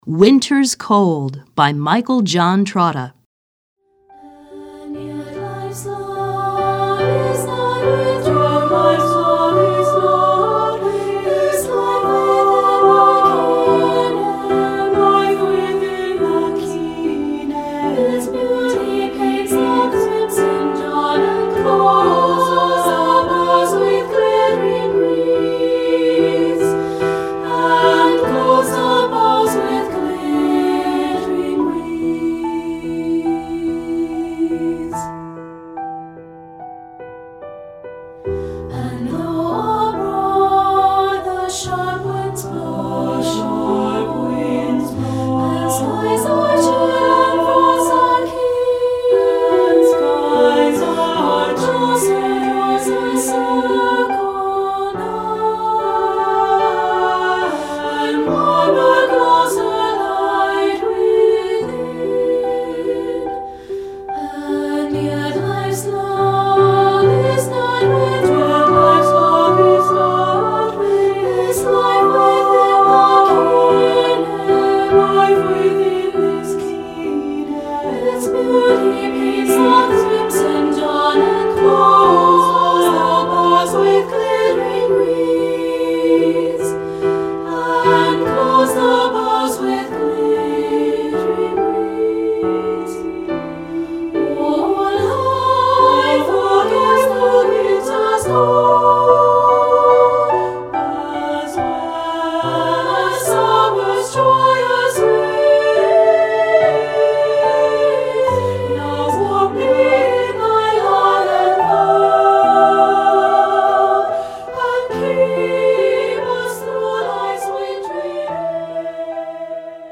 Choeur à 2 Voix